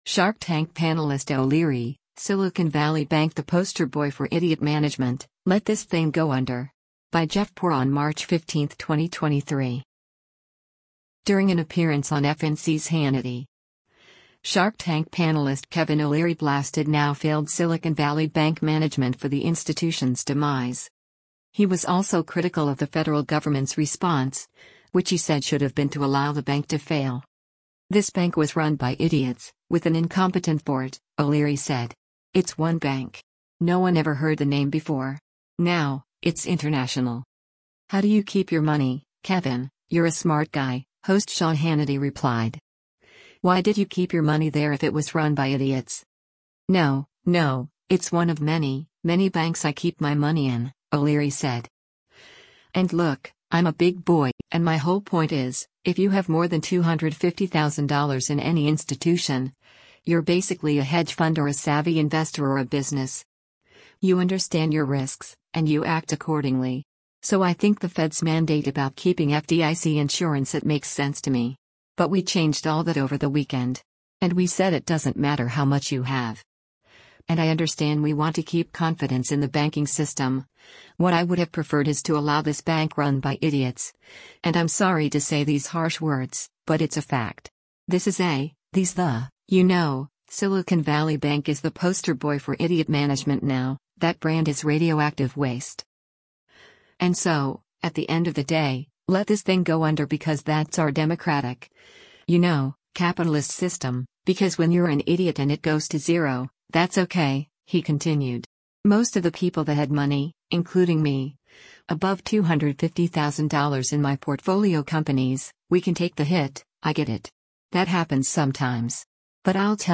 During an appearance on FNC’s “Hannity,” “Shark Tank” panelist Kevin O’Leary blasted now-failed Silicon Valley Bank management for the institution’s demise.